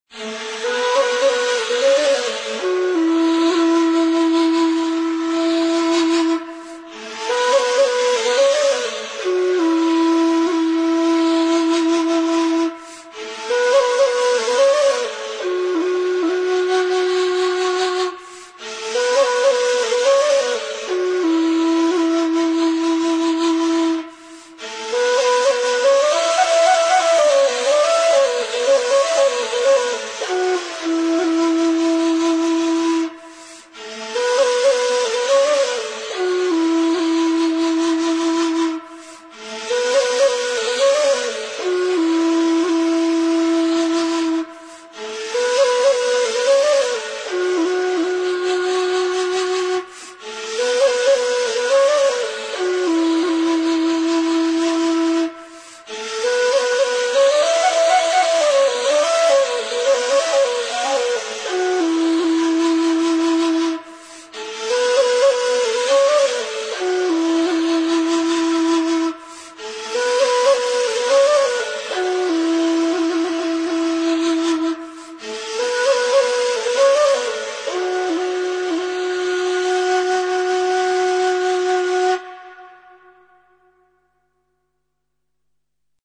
Песня [6]
Сыбызгы